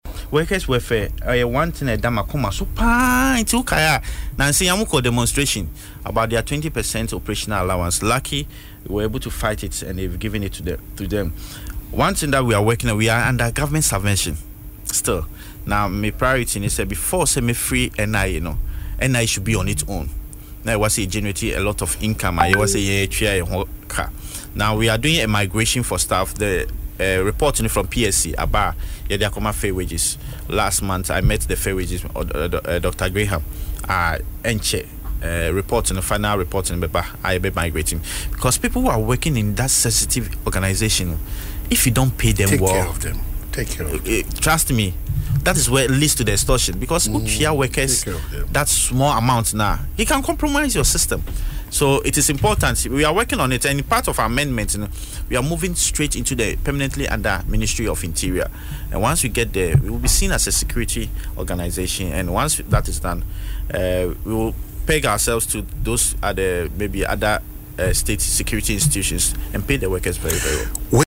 Speaking in an interview on Adom FM’s Dwaso Nsem, Mr. Deku disclosed that management had recently resolved issues concerning a 20 percent operational allowance for workers after a demonstration.